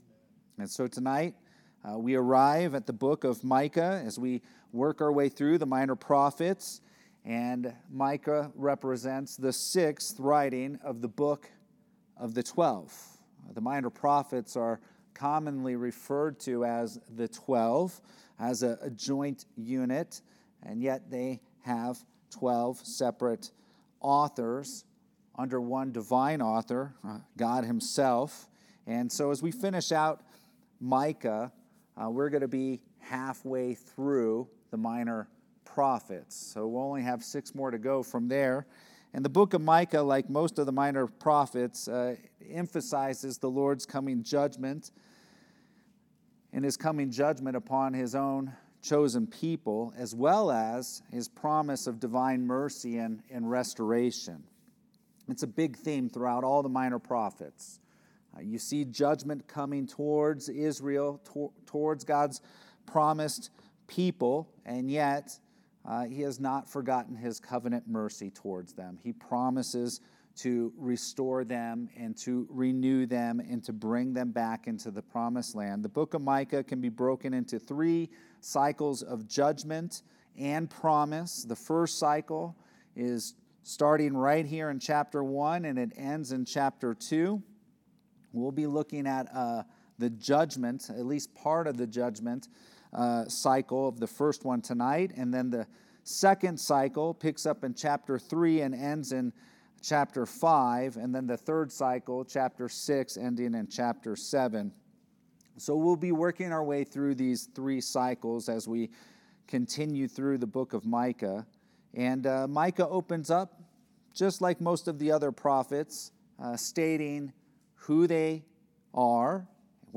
Sermons | Redemption Hill Church